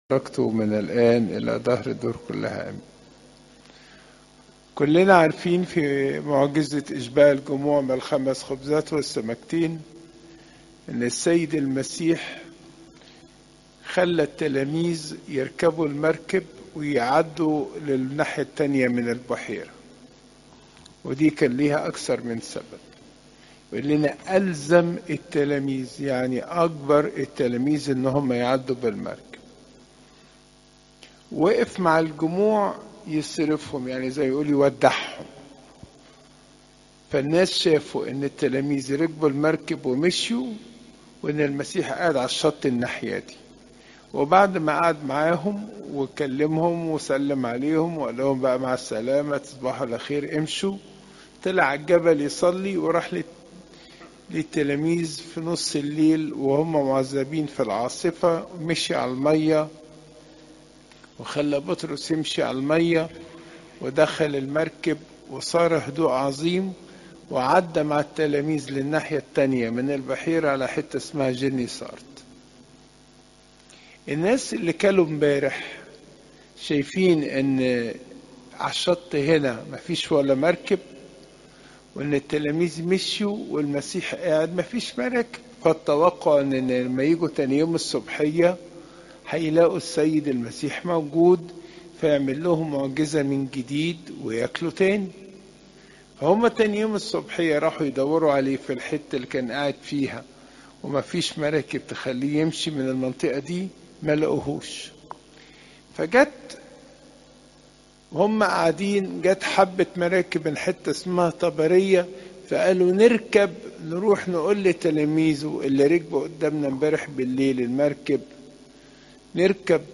عظات قداسات الكنيسة (يو 6 : 22 - 27)